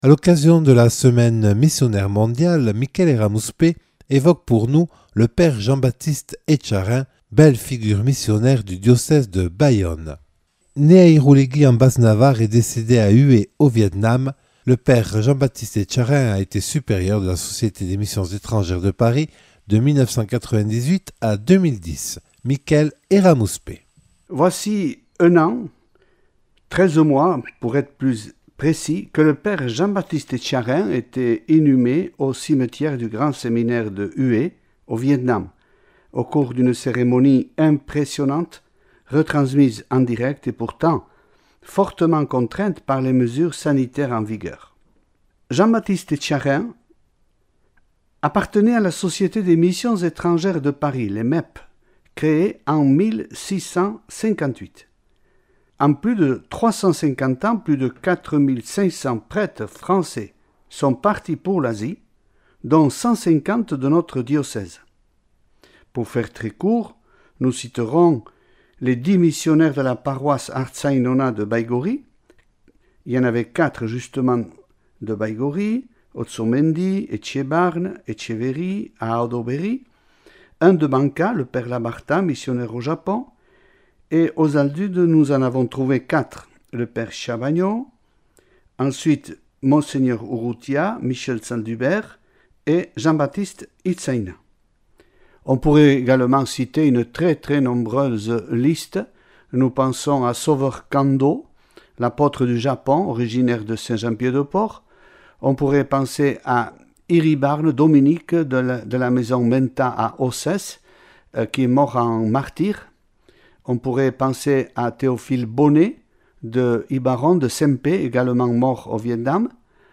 Témoignage filmé (à voir ci-dessous) le 22 septembre 2022 en l’église d’Irouléguy à l’occasion de la journée anniversaire des 30 ans de Radio Lapurdi Irratia.